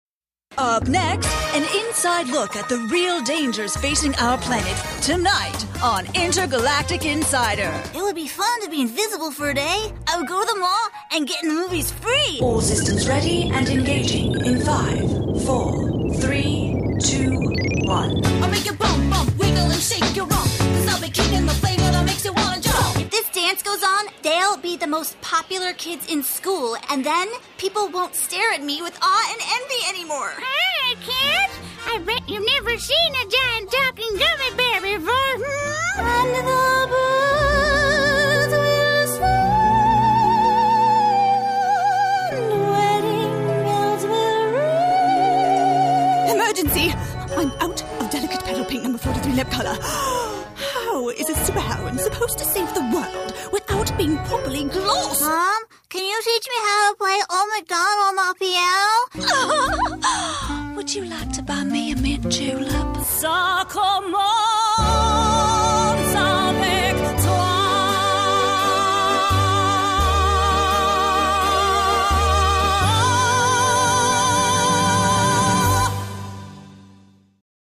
Fresh/hip, clear, bright, conversational ..and sassy/wry when it's called for.
englisch (us)
Sprechprobe: Sonstiges (Muttersprache):